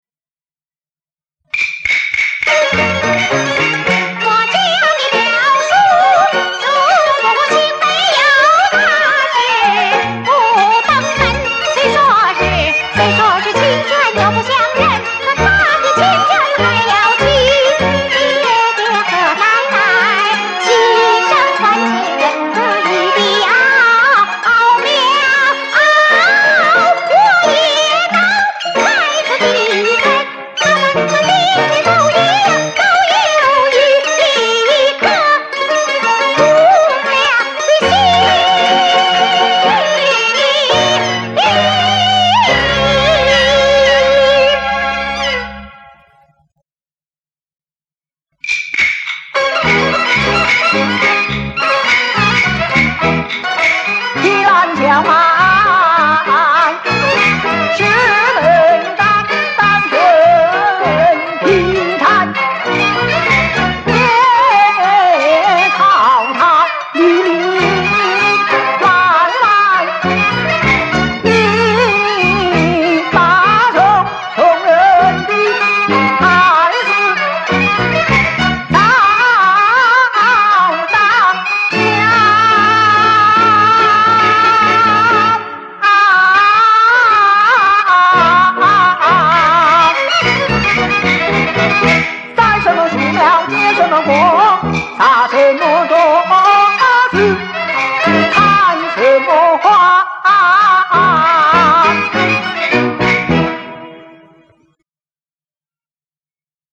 电声配器